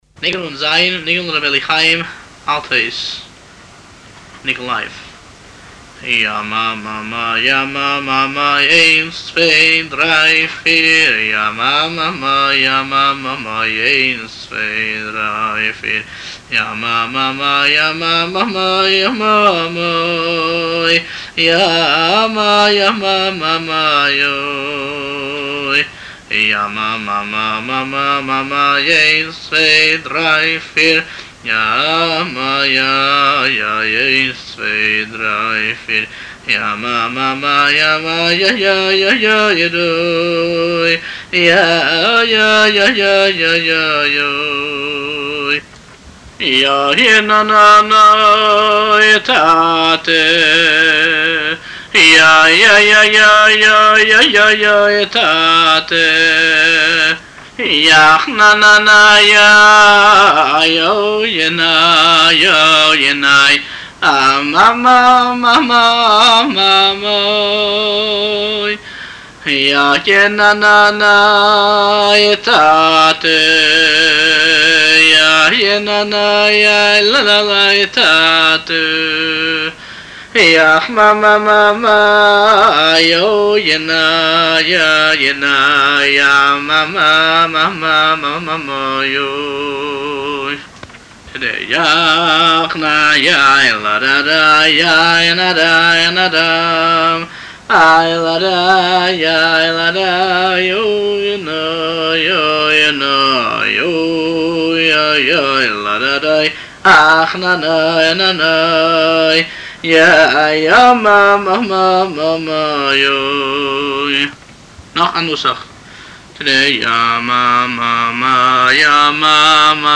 ניגון לר' אליהו חיים אלטהויז (נ"ז) – חב"דפדיה